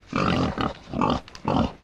flesh_idle_0.ogg